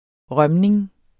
Udtale [ ˈʁɶmneŋ ]